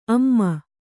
♪ amma